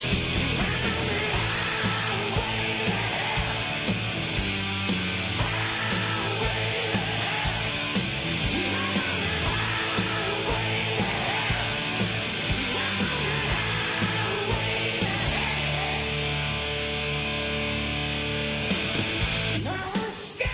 Rock Intros